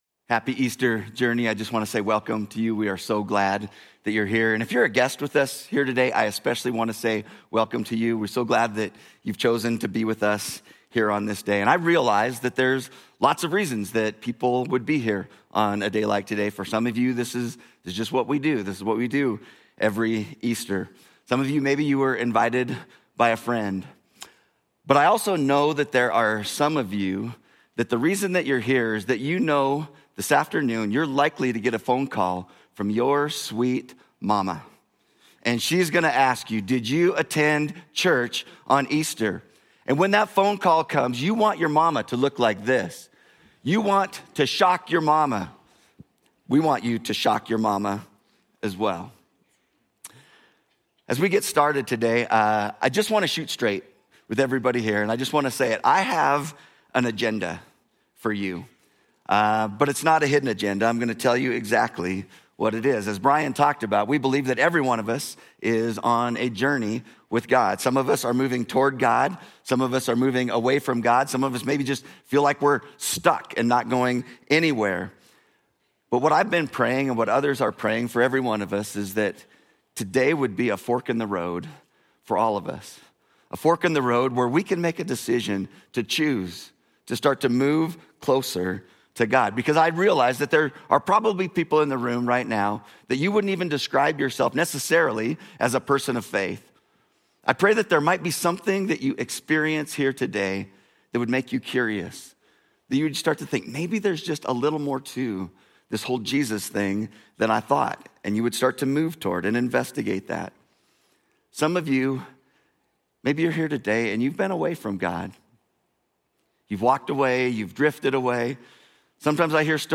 Journey Church Bozeman Sermons Easter: Is The Resurrection Plausible?